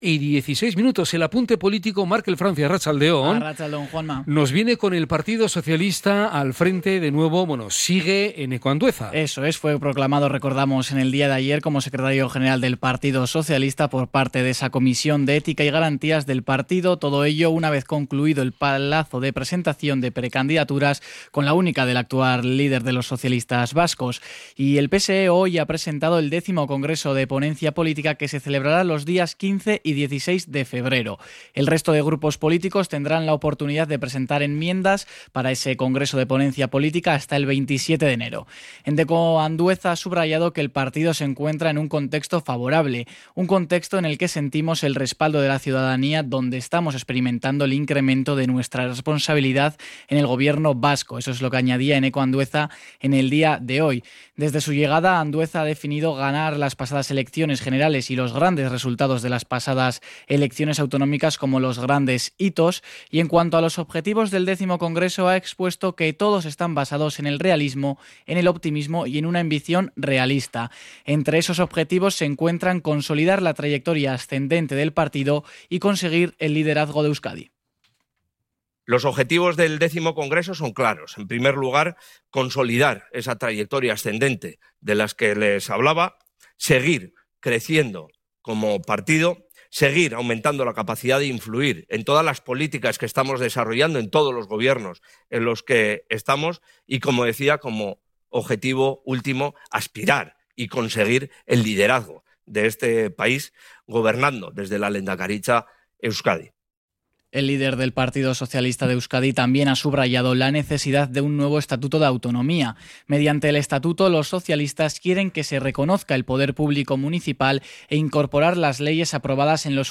Cronica-PSE.mp3